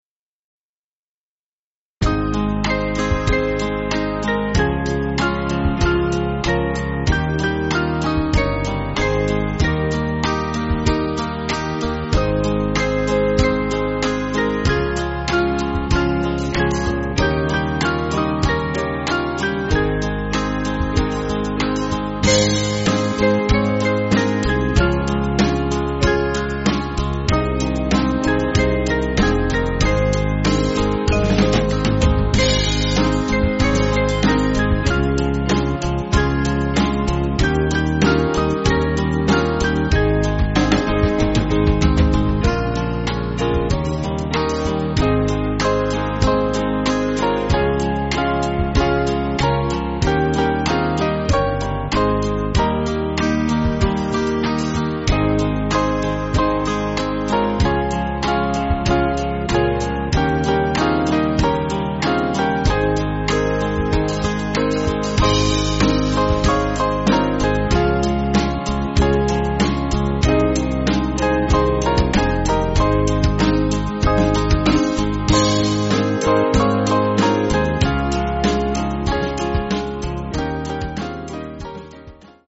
Small Band
(CM)   4/Ab